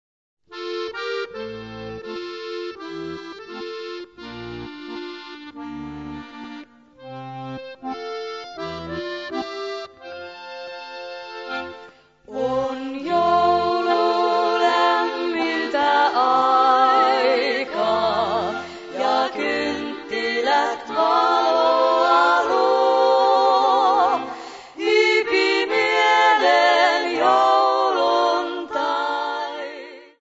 Laulut triona